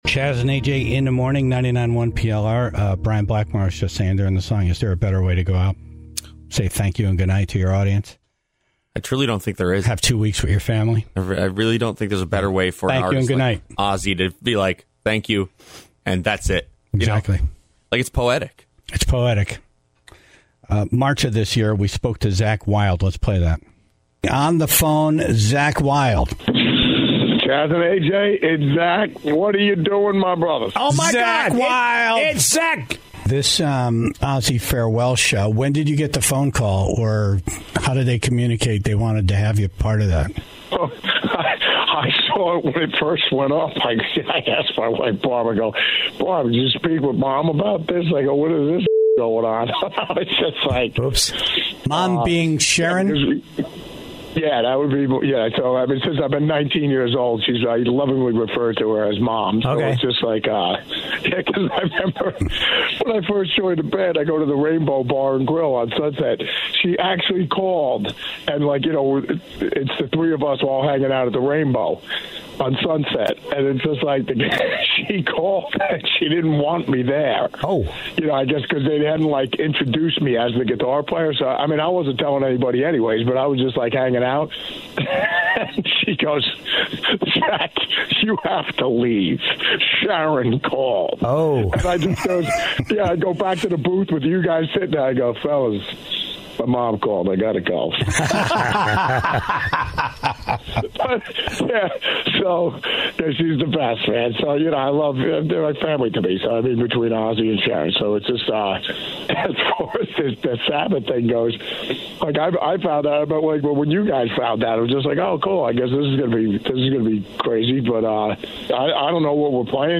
(44:10) Carmine Appice, who drummed with Ozzy, was on the phone to remember his friend and bandmate.
(53:25) Don Dokken was on the phone to talk about the Jam at the Dam coming to Connecticut this weekend, but spent most his time remembering Ozzy Osbourne.